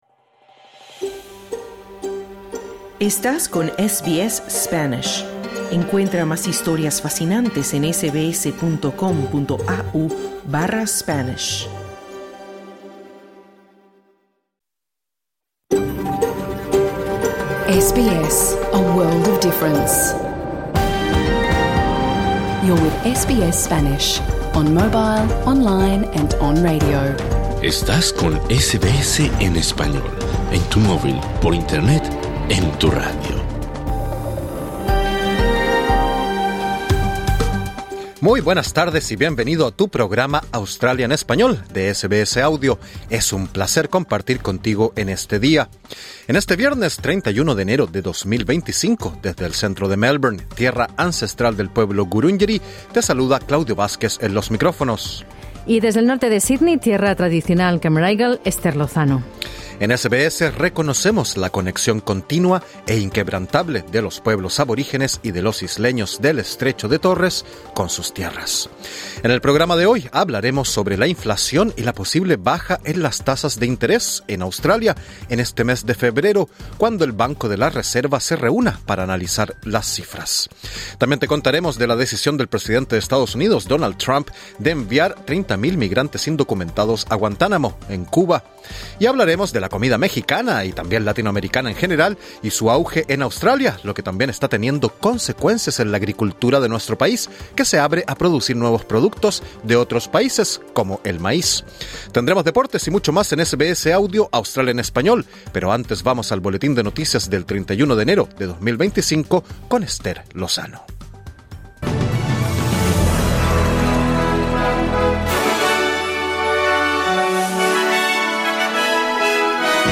Hablamos con un economista sobre la inflación y la posible baja en las tasas de interés en Australia en febrero, cuando el Banco de la Reserva organice su reunión para analizar las cifras. También te contamos de la decisión del presidente de Estados Unidos, Donald Trump, de enviar 30 mil migrantes indocumentados a Guantánamo, Cuba, y te traemos una charla sobre el auge de la comida mexicana y latinoamericana en Australia.